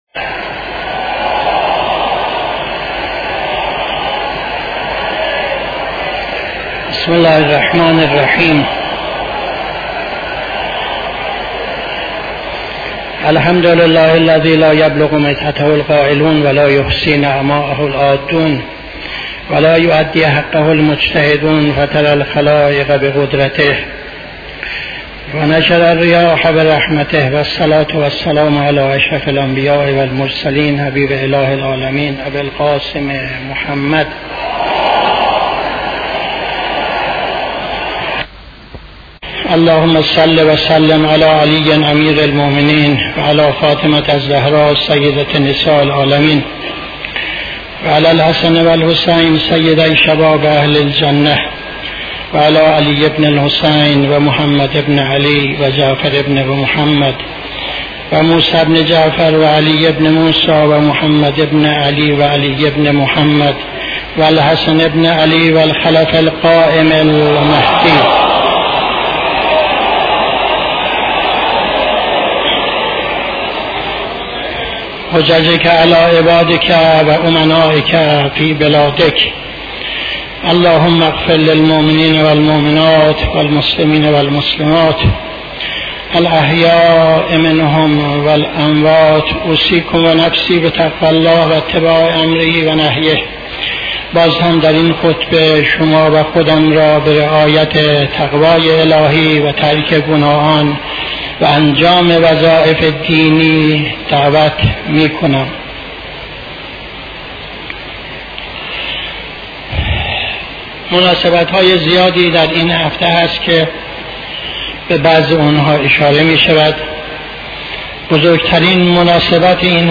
خطبه دوم نماز جمعه 12-04-77